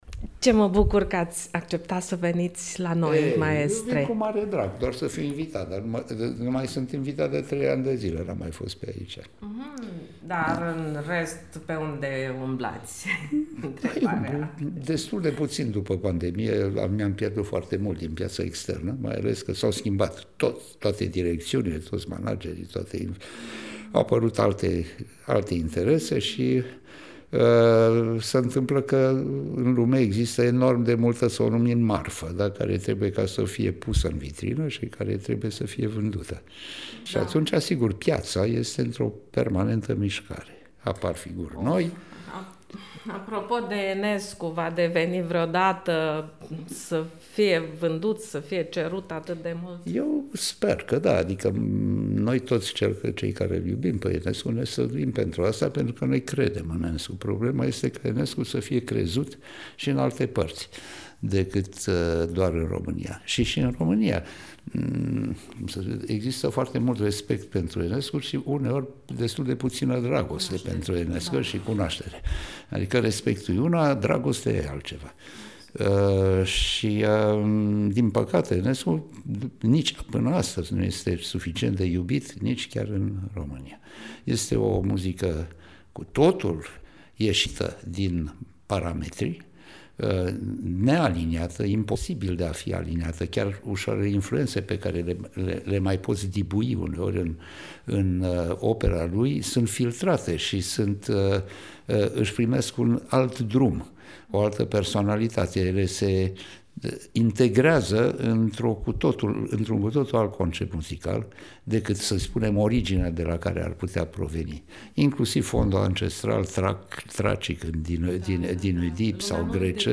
(AUDIO) Interviu cu maestrul Cristian Mandeal, Postfață la Recitalul Andrei Ioniță